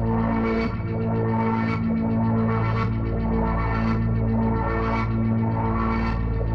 Index of /musicradar/dystopian-drone-samples/Tempo Loops/110bpm
DD_TempoDroneE_110-A.wav